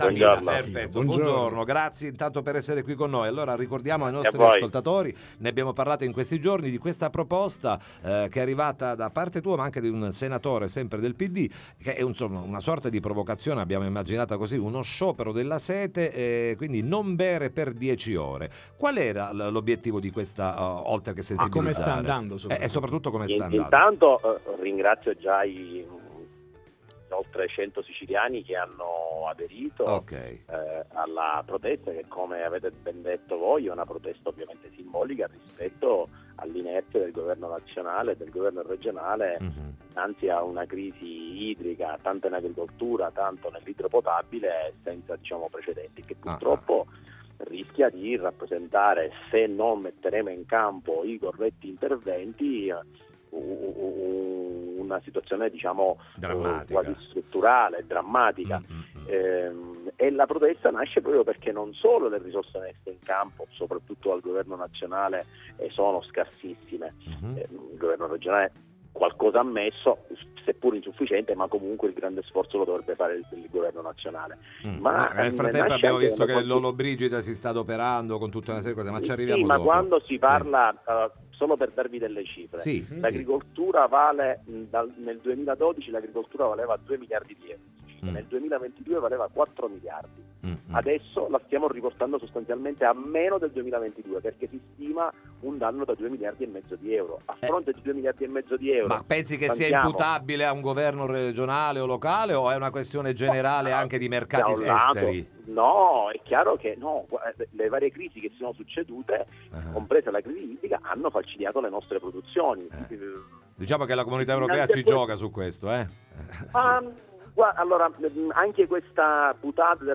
Sciopero della sete di 10 ore, ne parliamo con il dep. ARS Dario Safina (PD)